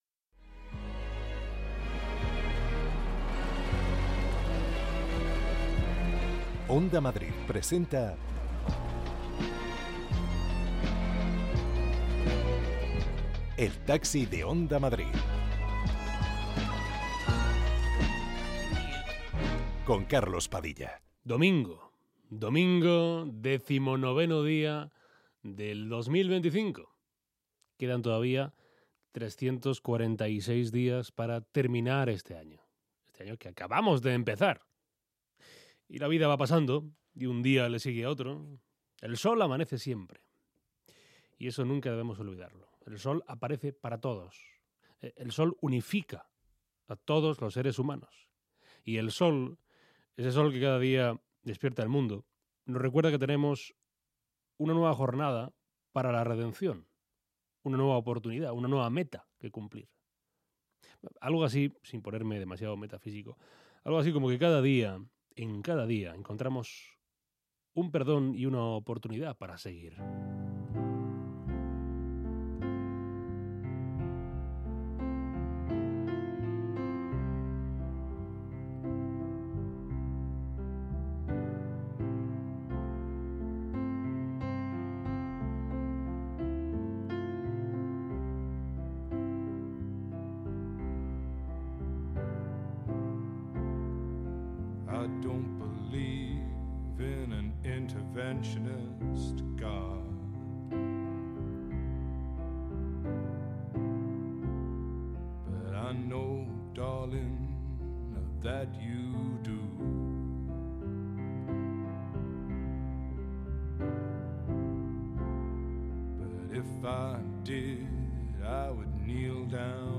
Conversaciones para escapar del ruido. Recorremos Madrid con los viajeros más diversos del mundo cultural, político, social, periodístico de España...